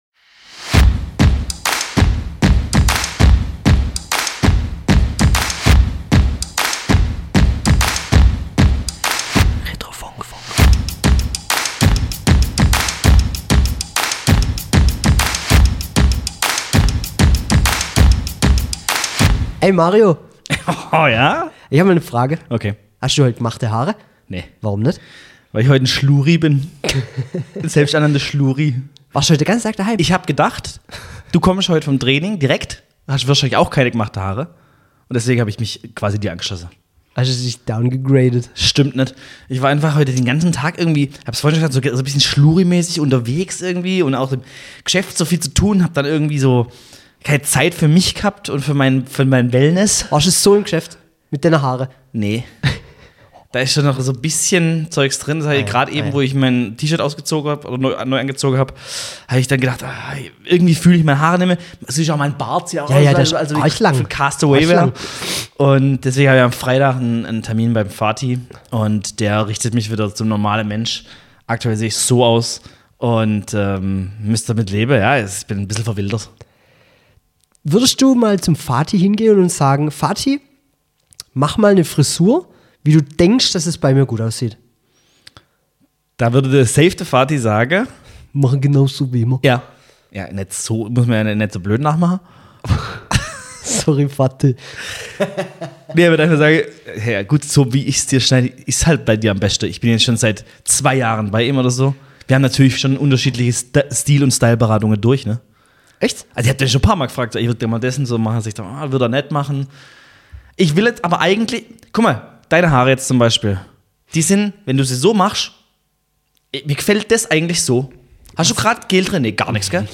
Retrofunk ist Nostalgie, Fußball-Kabine, Kneipentisch und Serien-Deep-Talk in einem. Ehrlich, direkt, manchmal komplett daneben – aber immer mit Herz. Zwei Mikros.